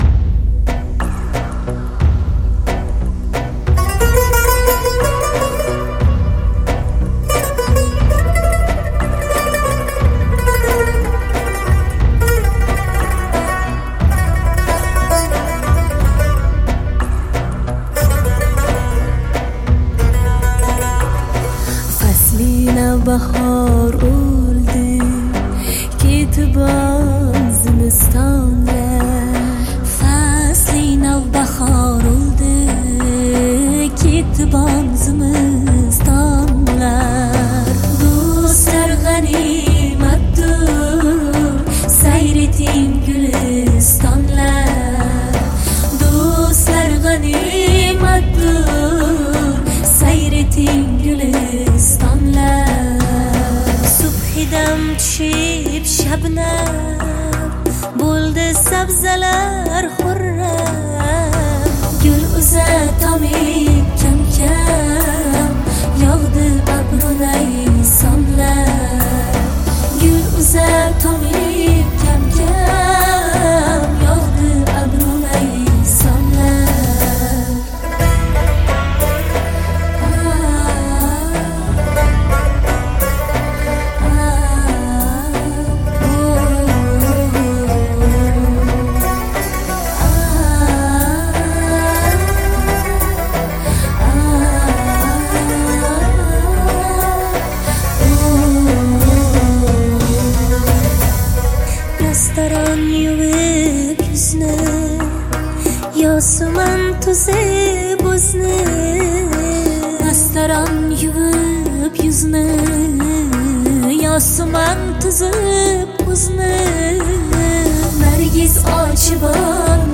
• Жанр: Узбекская музыка